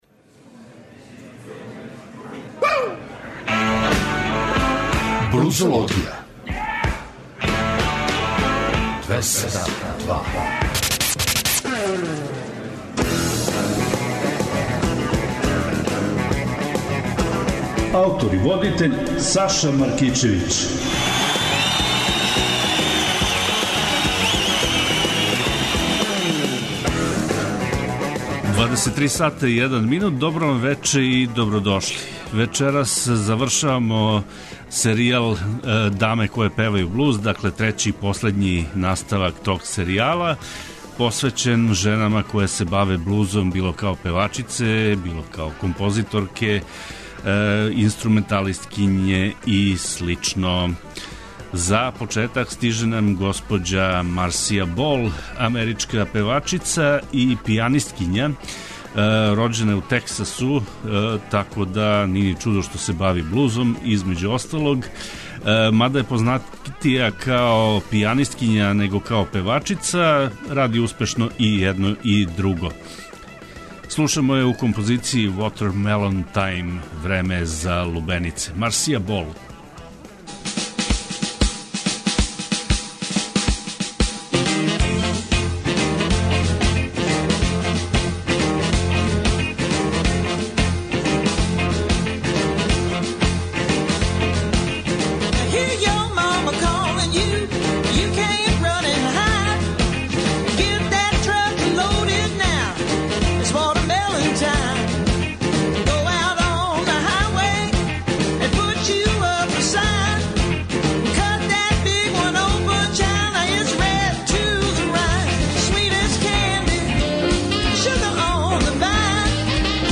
Поштујући захтеве многих слушалаца, Београд 202 је од 10. јануара 2015. покренуо нову музичку емисију под називом „Блузологија“.
Суботом од 23.00 до поноћи, нудимо вам избор нових музичких издања из овог жанра, али не заборављамо ни пионире који су својим радом допринели развоју блуза и инспирисали младе музичаре широм света да се заинтересују и определе за професионалну каријеру у овој области.